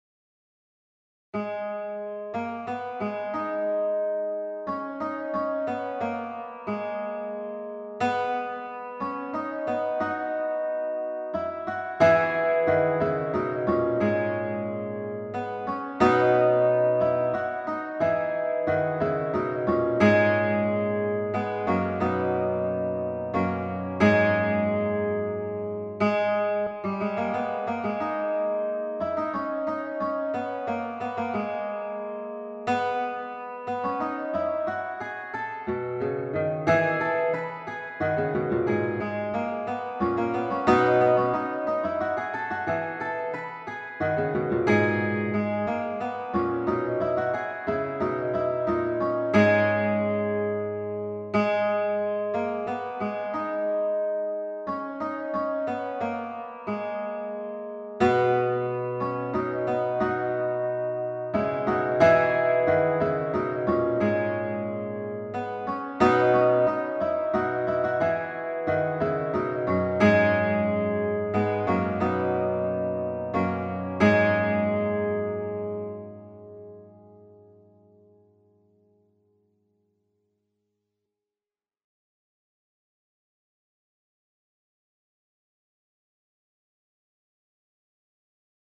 in a -